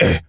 gorilla_hurt_02.wav